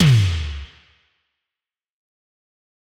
Tom_G3.wav